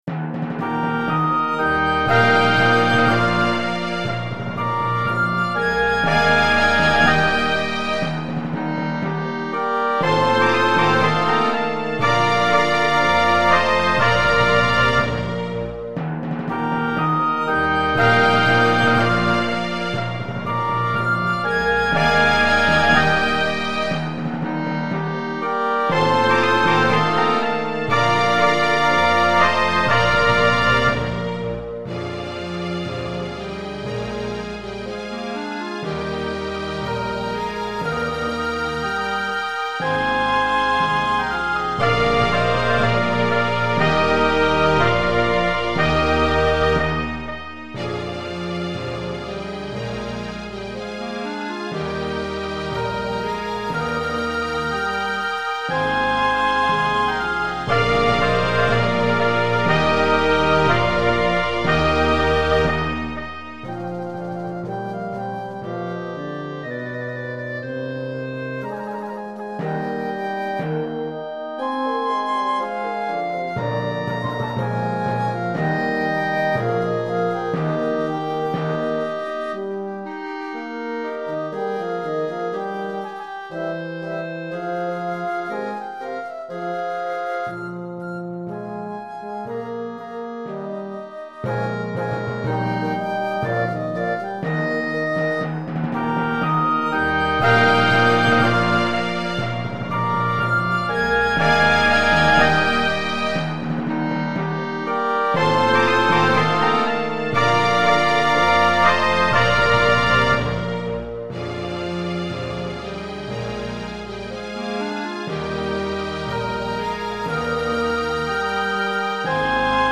strumentale